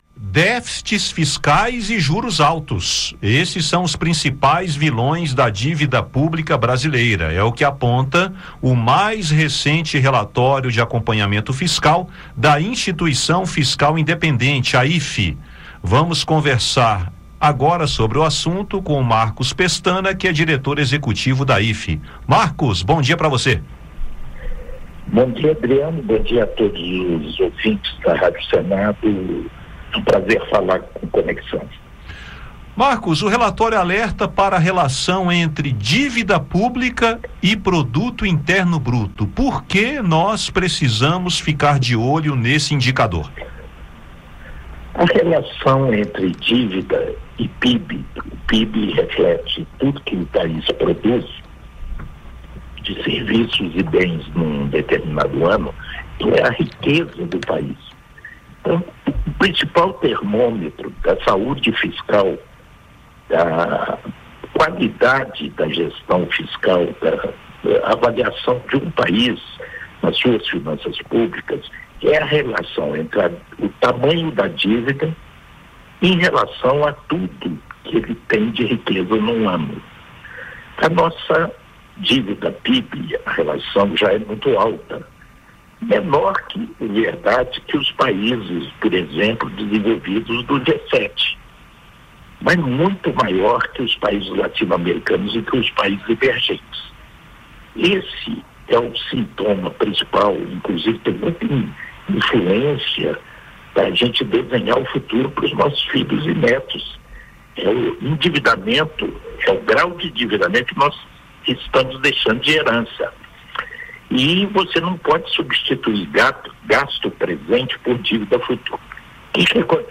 Os principais vilões da dívida pública brasileira são déficits fiscais e juros altos. É o que aponta o mais recente Relatório de Acompanhamento Fiscal da Instituição Fiscal Independente (IFI). Marcus Pestana, diretor-executivo da IFI, explica o indicador do relatório que aponta relação entre a dívida pública e o produto interno bruto, as principais medidas que devem ser tomadas para contornar situações críticas e como isso afeta o cidadão.